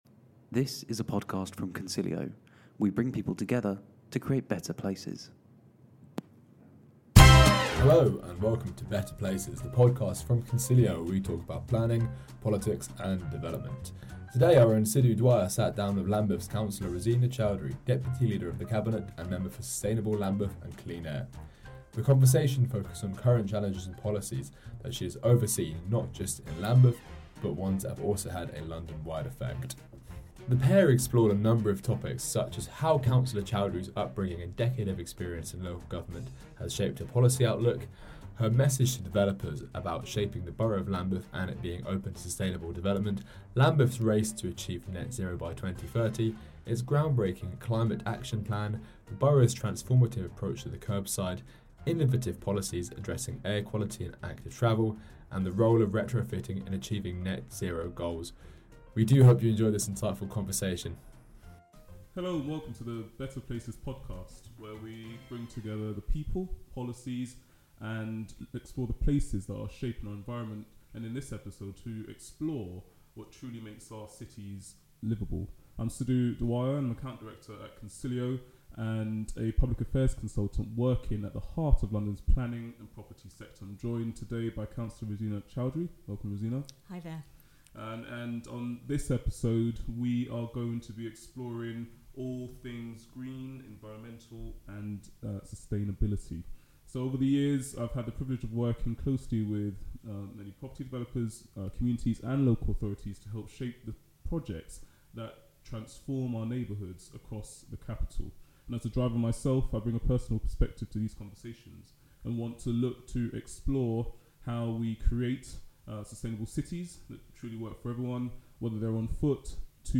We do hope you enjoy this insightful conversation, you can listen to the episode here .